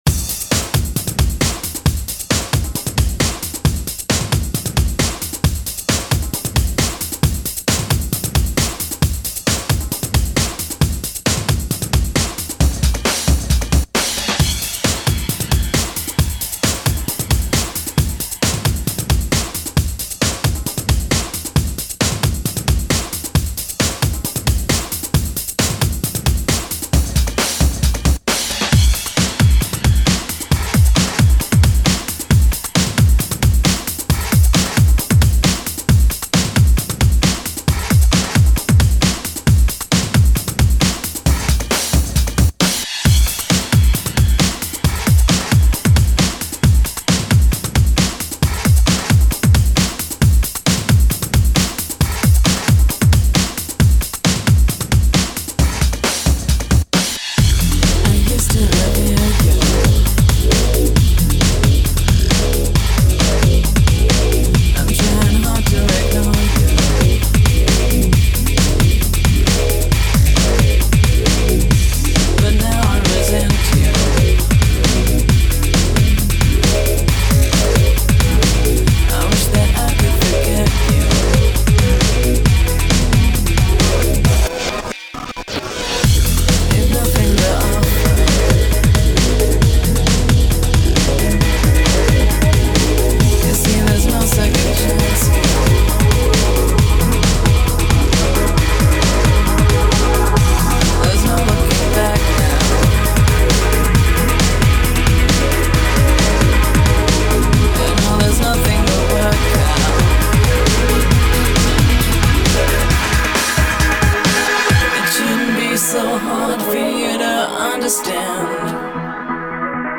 2011-й год / breaks / trance